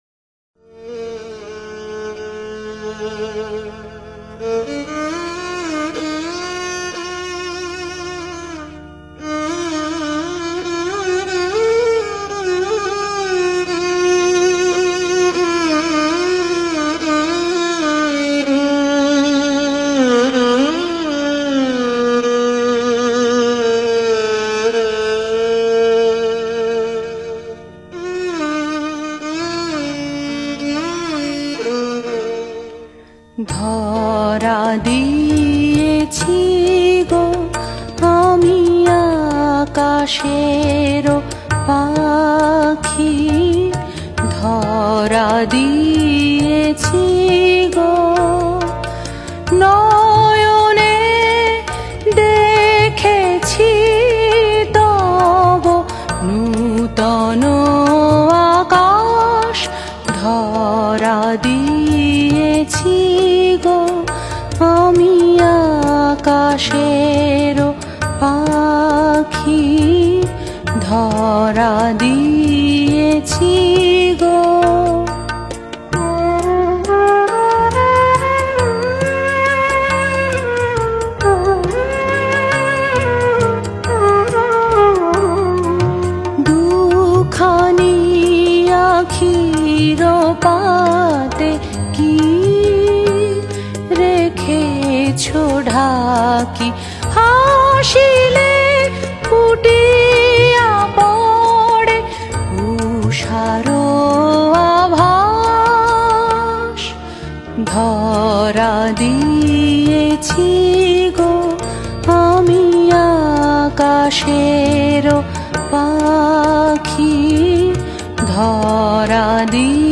Genre Rabindra Sangeet